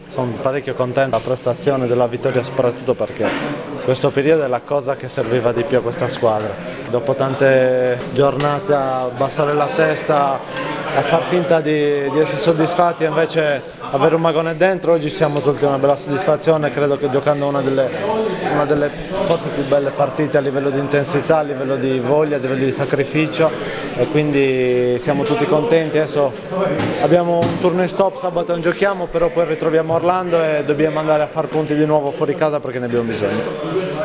Interviste post partita: